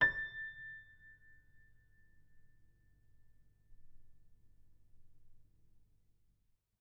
sampler example using salamander grand piano
A6.ogg